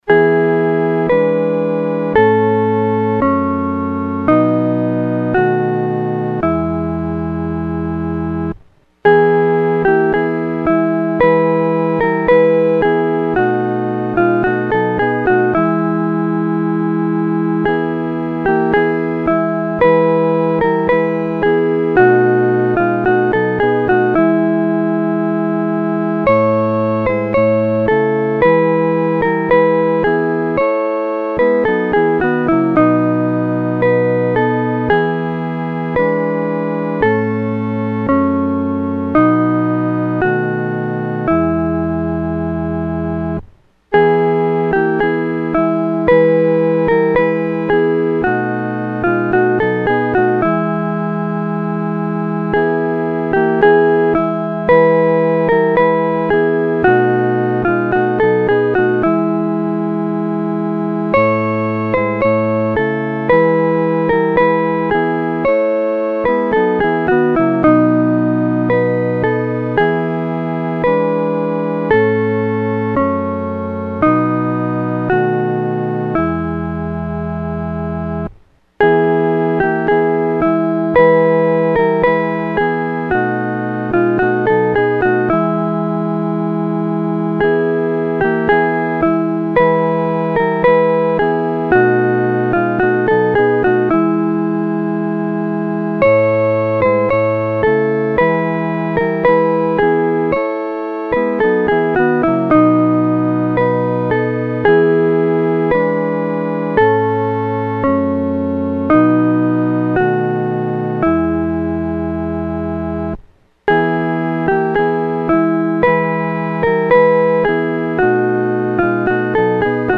合奏（四声部）
赞父奇爱-合奏（四声部）.mp3